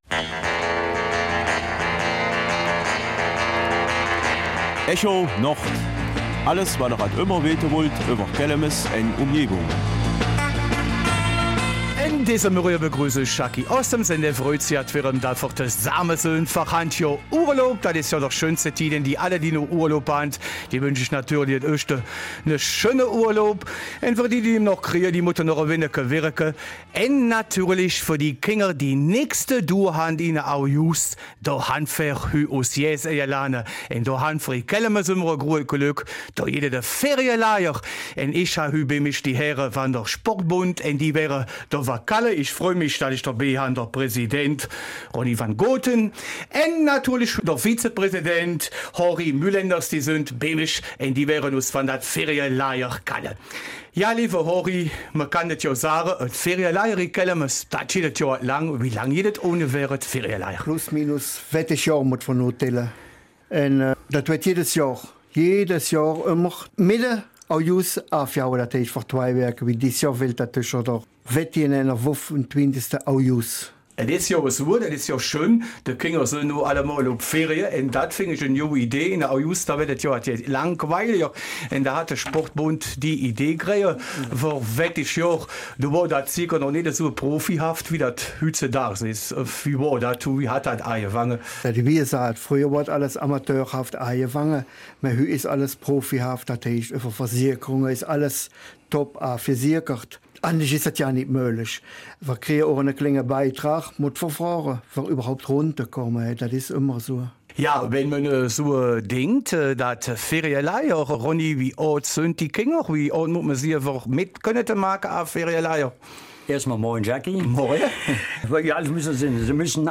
Kelmiser Mundart: Sport- Freizeitlager in Kelmis